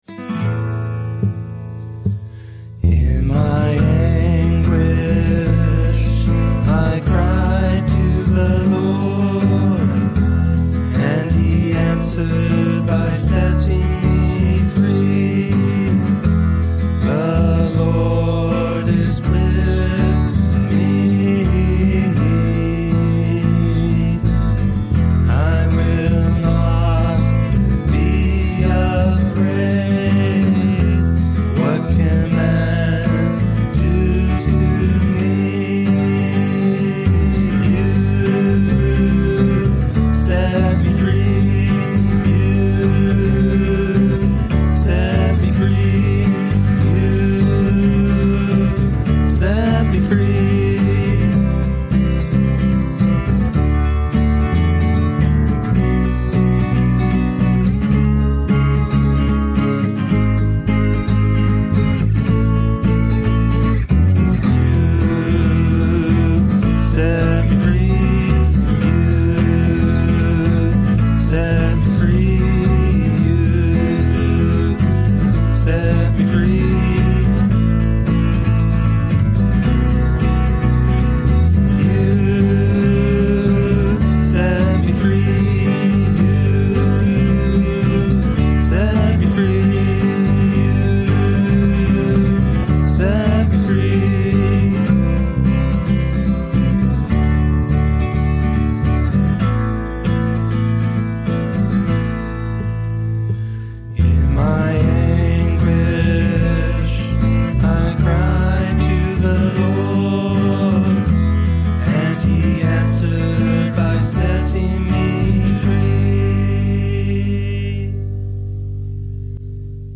I play bass & guitar, and sing. The sort of chorus effect at the beginning is simply me singing the same thing twice.
I like the chord progression, especially the build up to the first key change.